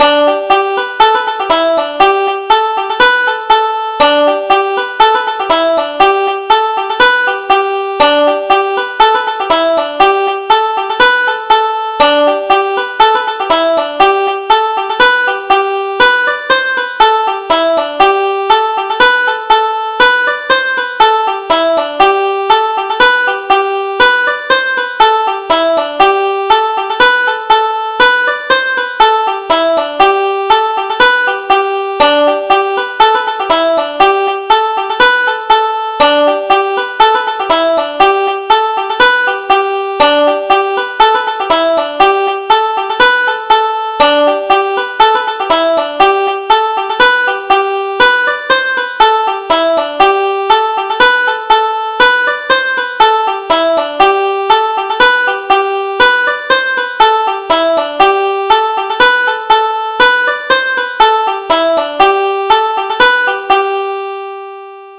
Irish Traditional Polkas